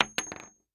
Bullet Shell Sounds
rifle_wood_8.ogg